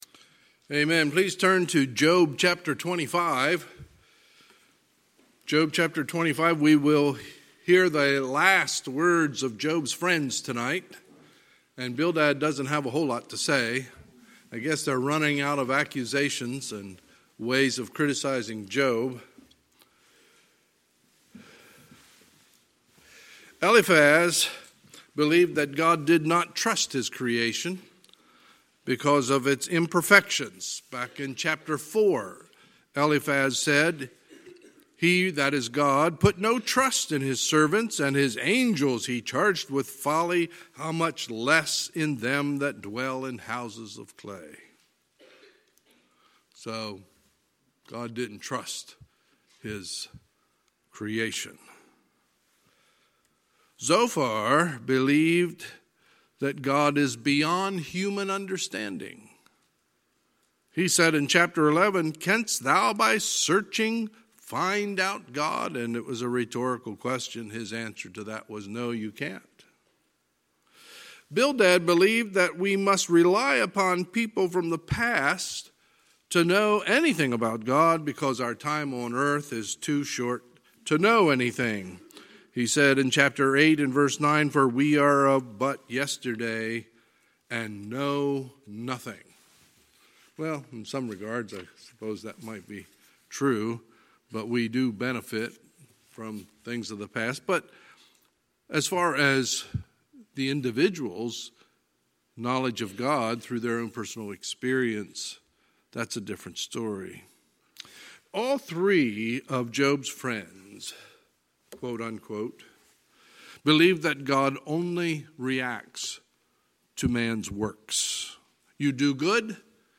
Sunday, March 15, 2020 – Sunday Evening Service
Sermons